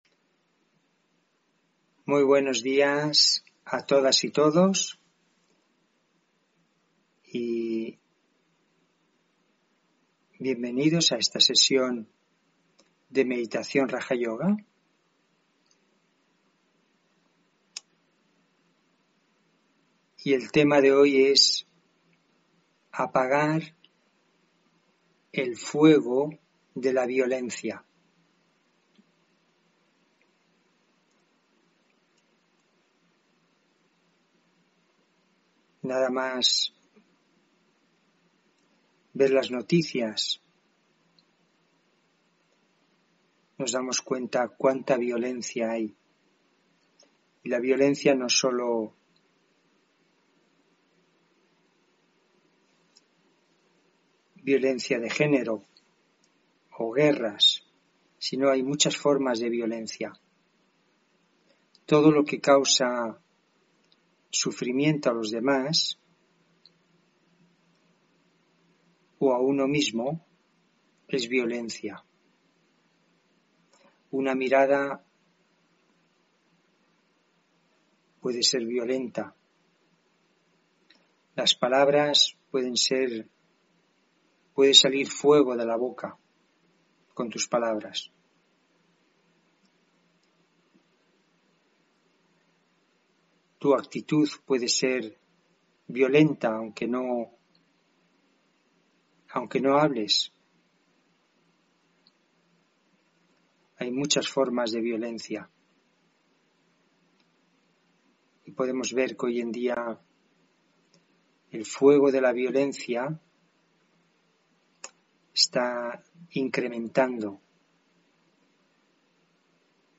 Meditación de la mañana: Apagar el fuego de mi violencia interior